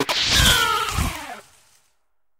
Cri de Miascarade dans Pokémon HOME.